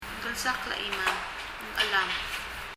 “Ng chelam.” chelam [? ɛləm] くじく、捻挫する